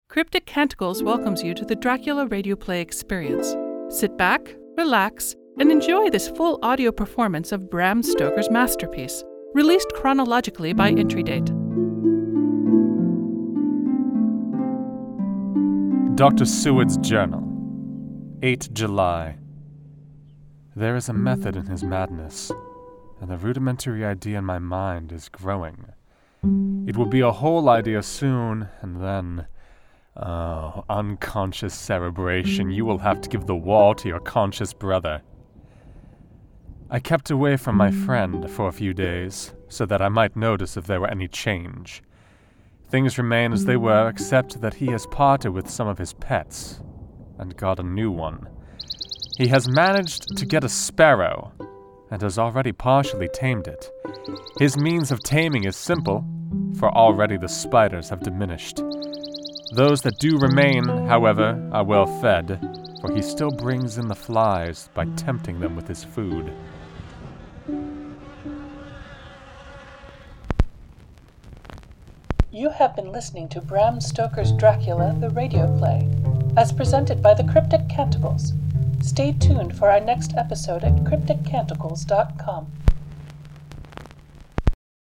This is a rebroadcast of the original 2017 work.